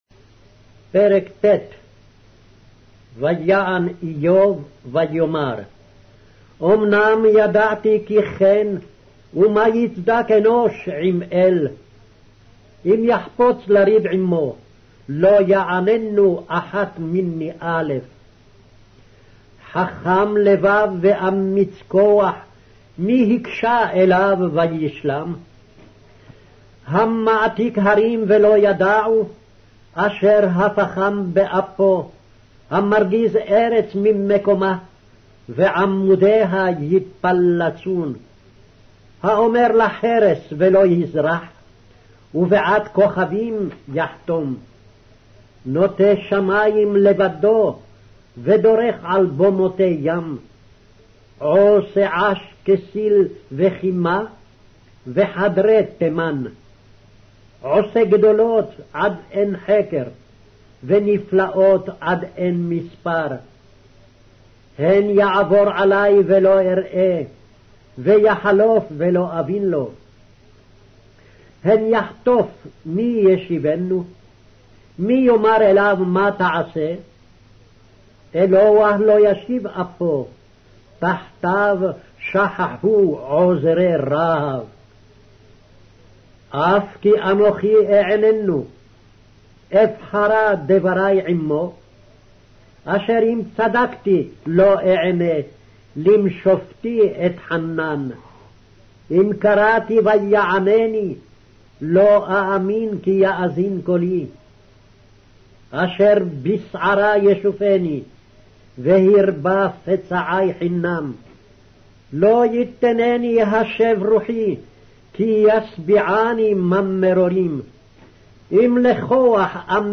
Hebrew Audio Bible - Job 32 in Mrv bible version